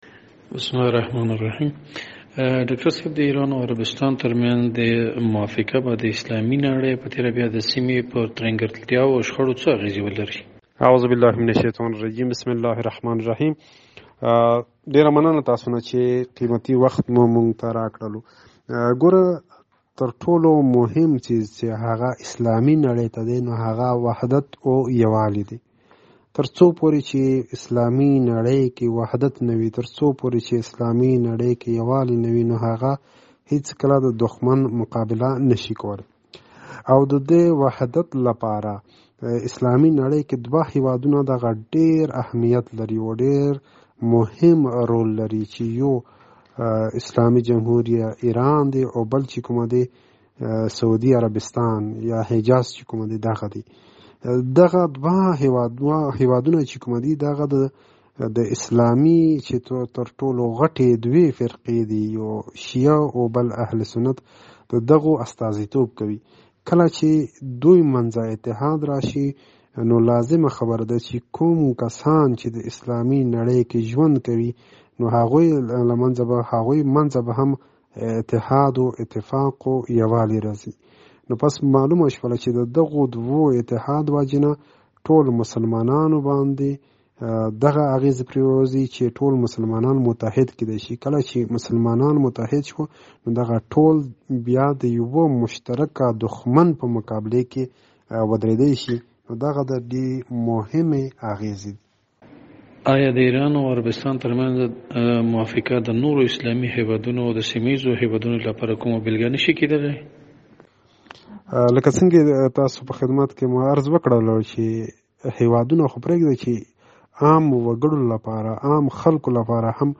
د ایران او عربستان روغې جوړې استعماری لړۍ لالهانده کړې ده( مرکه)